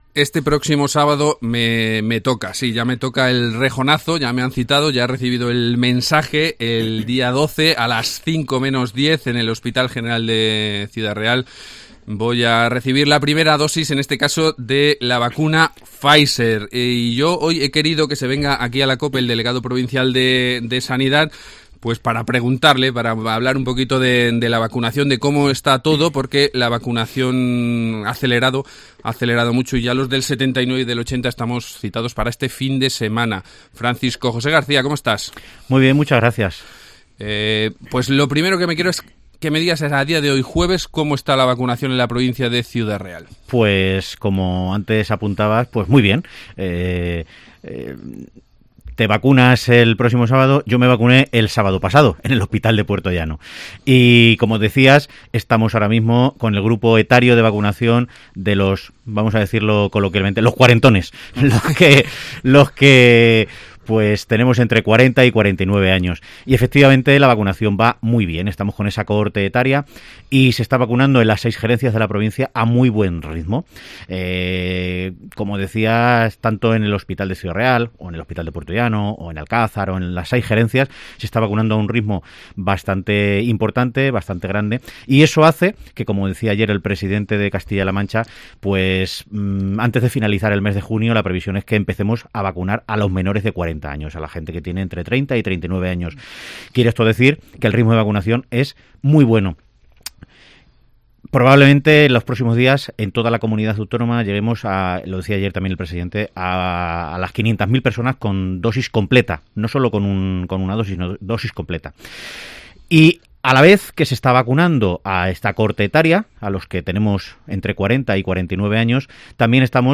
AUDIO: Entrevista Francisco José García, delegado de Sanidad